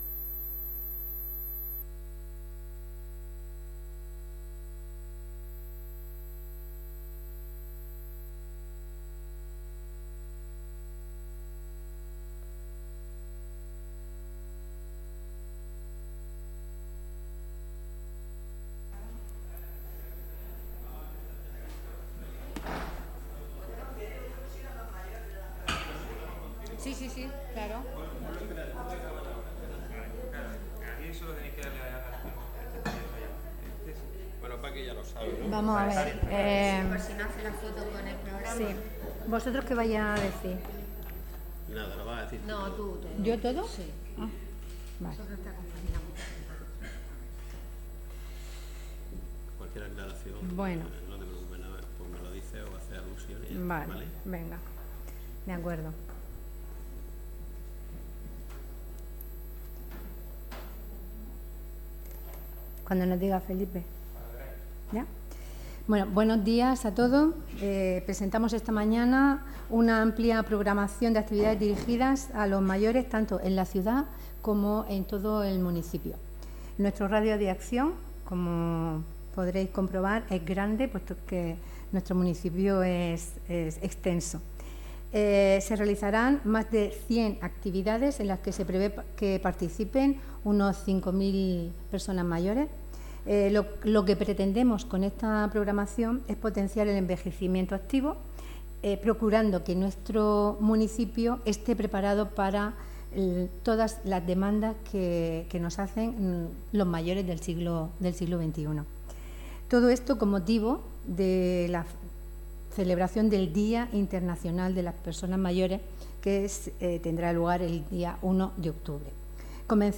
Enlace a Declaraciones Francisca Martínez Sotomayor